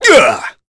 Chase-Vox_Attack1.wav